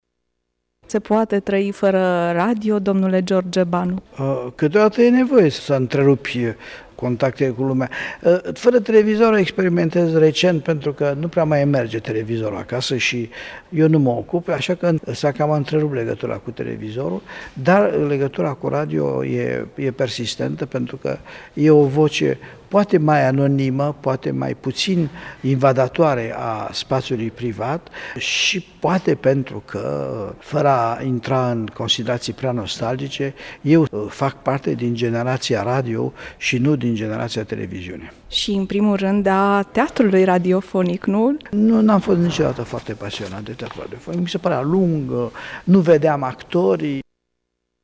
Cu sau fără Radio? L-am întrebat în preajma Zilei Radioului pe teatrologul George Banu, aflat la Tg.Mureș pentru a îmbrăca haina distincției Doctor Honoris Causa al Universității „Petru Maior” din Tg. Mureș.